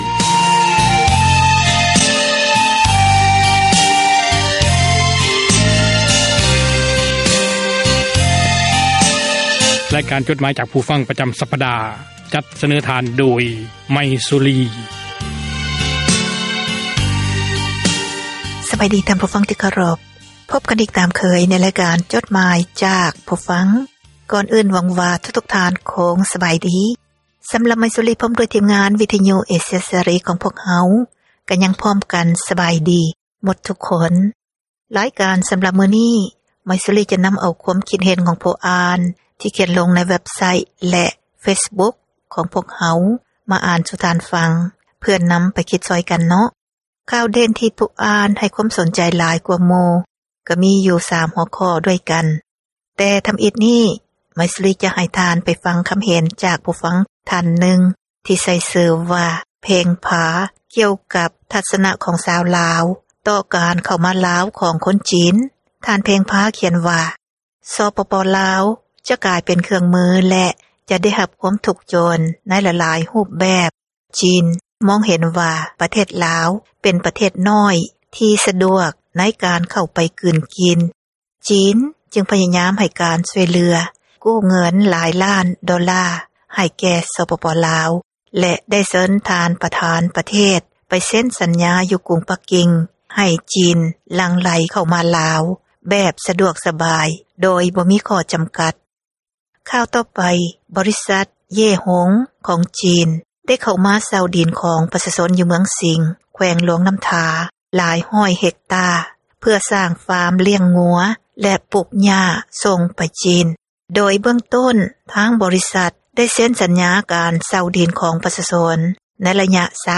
ອ່ານຈົດໝາຍ, ຄວາມຄຶດຄວາມເຫັນ ຂອງທ່ານ ສູ່ກັນຟັງ ເພື່ອເຜີຍແພ່ ທັສນະ, ແນວຄິດ ທີ່ສ້າງສັນ, ແບ່ງປັນ ຄວາມຮູ້ ສູ່ກັນຟັງ.